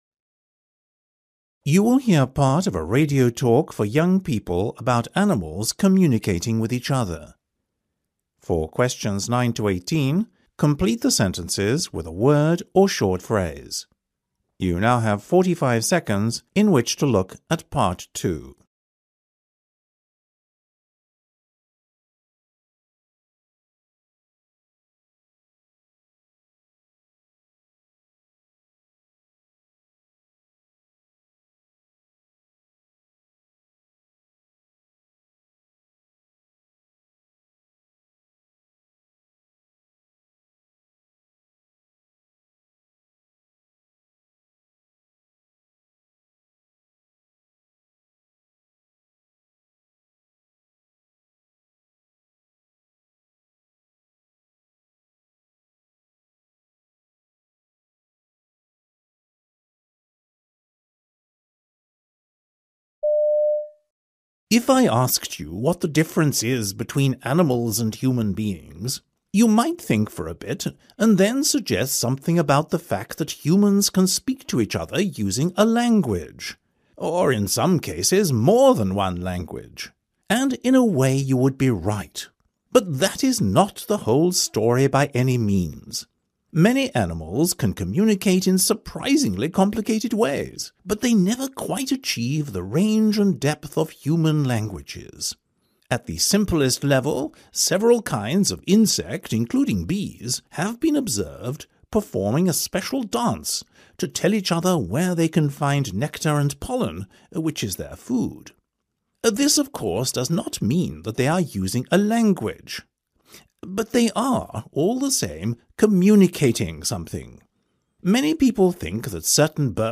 You will hear part of a radio talk for young people about animals communicating with each other.